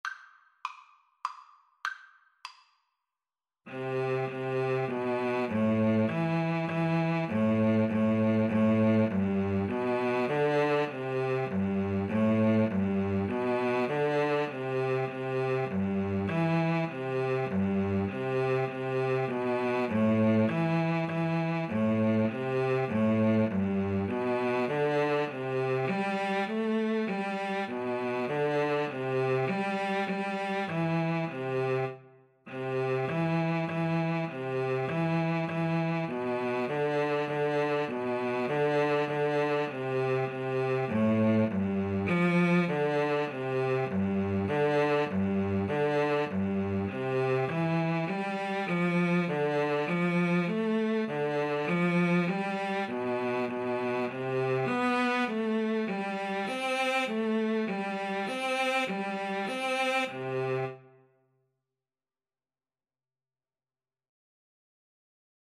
Cello 1Cello 2
3/4 (View more 3/4 Music)